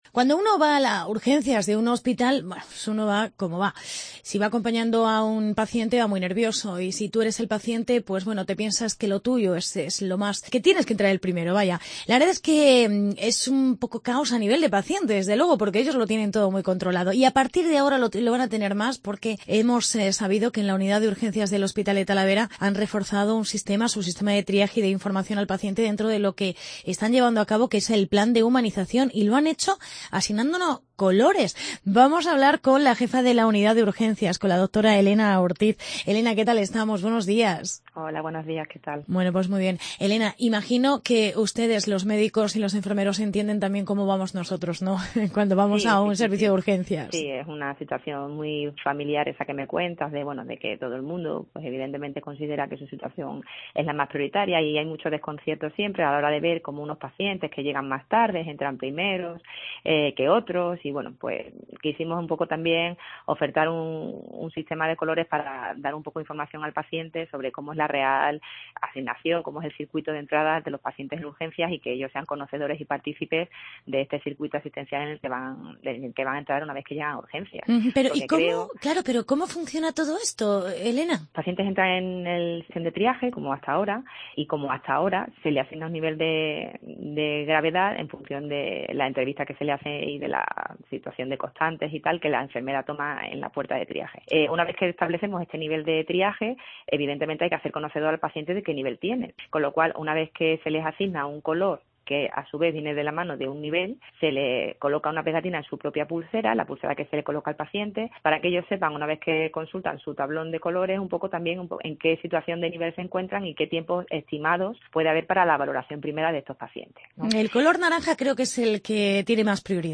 Entrevista con la jefa de la Unidad de Urgencias